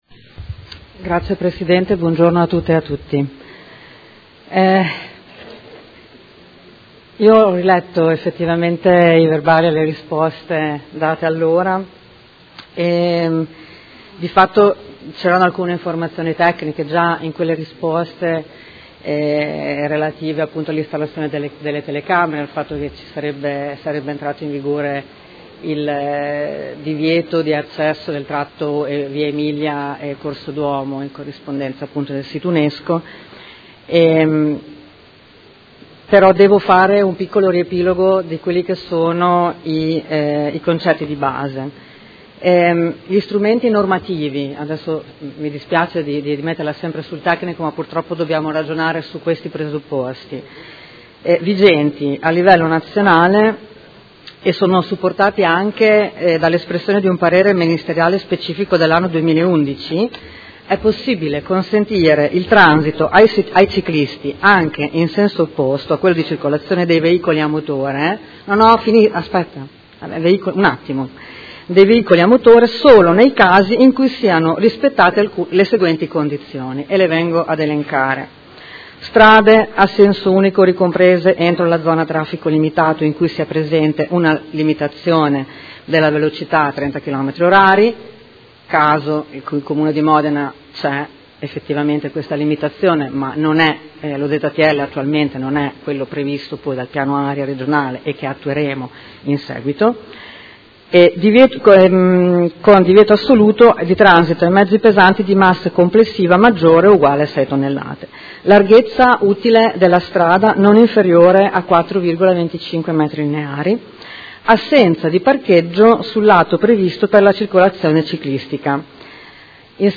Seduta del 22/11/2018. Risponde a interrogazione dei Consiglieri Rabboni, Fantoni, Scardozzi e Bussetti (M5S) avente per oggetto: Revisione sensi unici centro storico